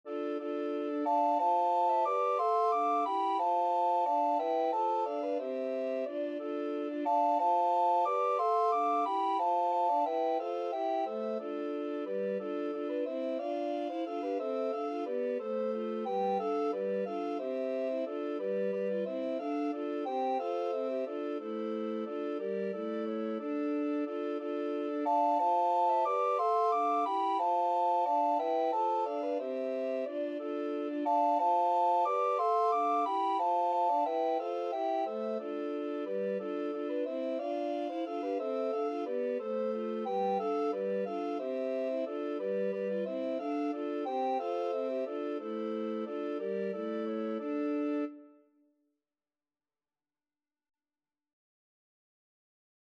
Den hoboeken dans Recorder Quartet version
Soprano RecorderAlto RecorderTenor RecorderBass Recorder
6/4 (View more 6/4 Music)
A minor (Sounding Pitch) (View more A minor Music for Recorder Quartet )
Classical (View more Classical Recorder Quartet Music)